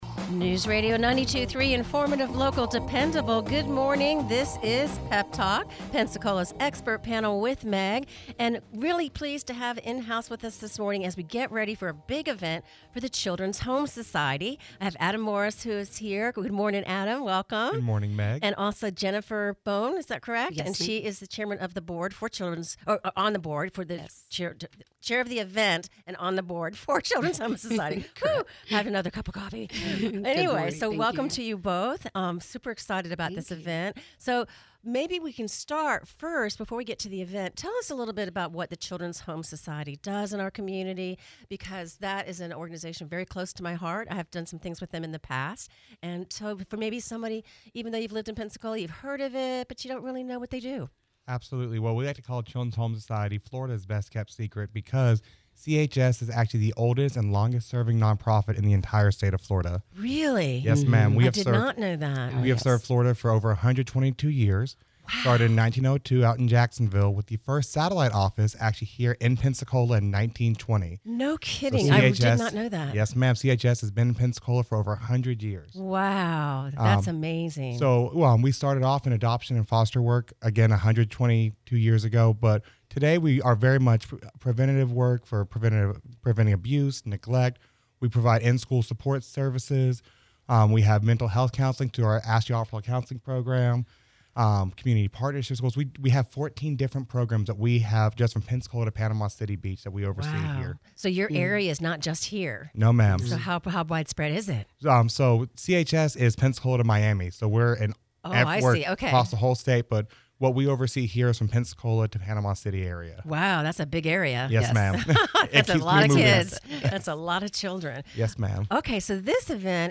Encore broadcast